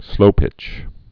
(slōpĭch)